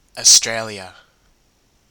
Úc hay Australia, Úc Châu, Úc Đại Lợi (phát âm tiếng Anh: /əˈstrljə, ɒ-, -iə/
En-au-Australia.ogg.mp3